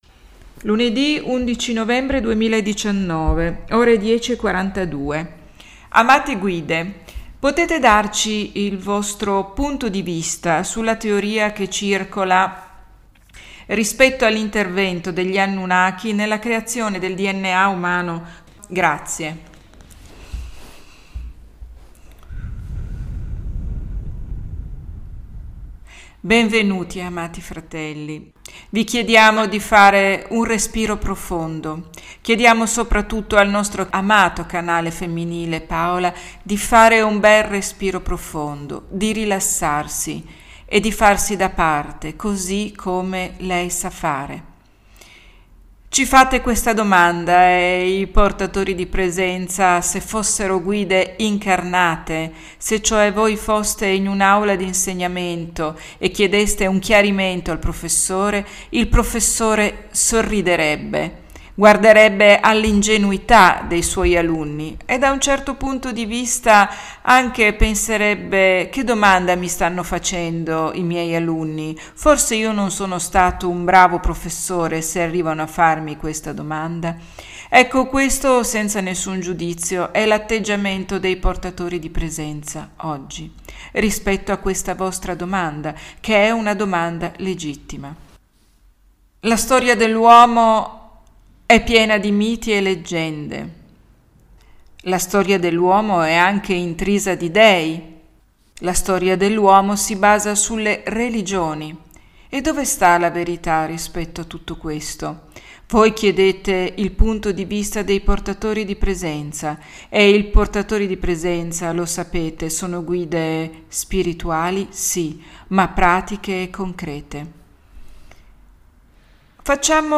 [clicca e ascolta la registrazione integrale della sessione di channeling]